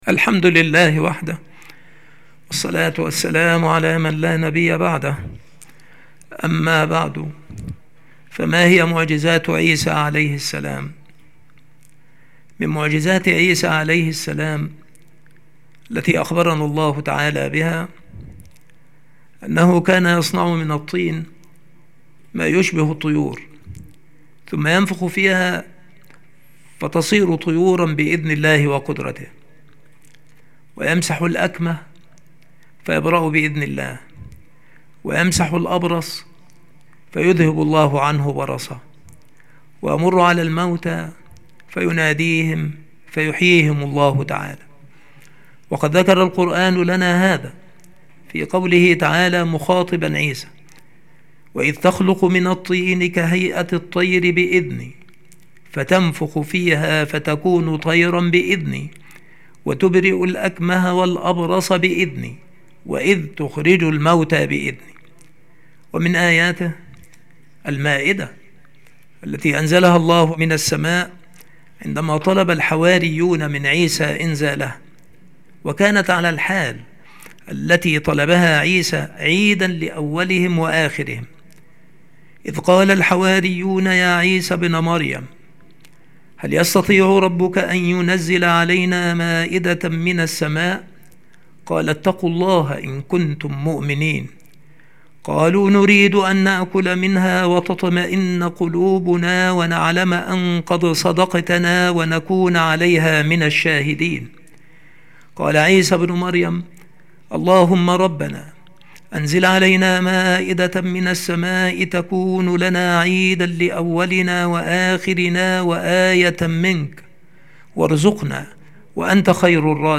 • مكان إلقاء هذه المحاضرة : المكتبة - سبك الأحد - أشمون - محافظة المنوفية - مصر